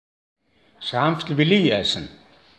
Hier ist die akustische Variante dieses Dialektwortes aus Osttirol:
Es-ranftl-will-i-essn.m4a